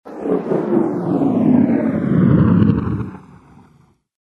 Звуки кометы
Шум кометы в полете над небом